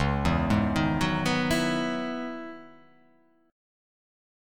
Dbm11 chord